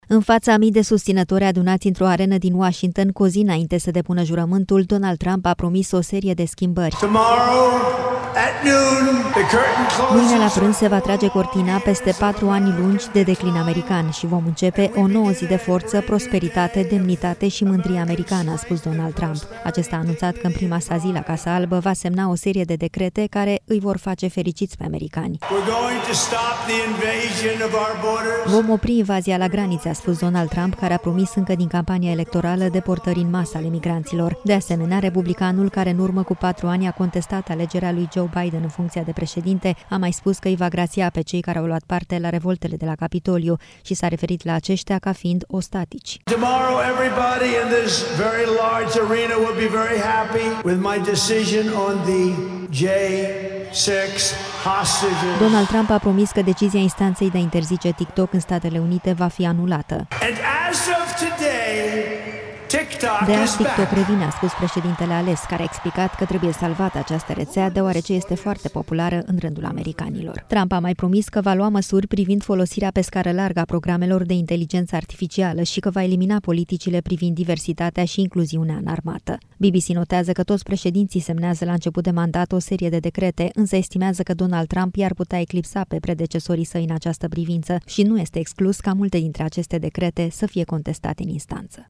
Cu o zi înainte de a reveni la putere –  în faţa a mii de susţinători adunați într-o sală de sport ce va găzdui o parte din festivități – Donald Trump a promis că va inaugura o nouă eră de prosperitate americană.
În fața a mii de susținători adunați într-o arenă din Washington cu o zi înainte ca președintele ales să depună jurământul, Donald Trump a promis o serie de schimbări: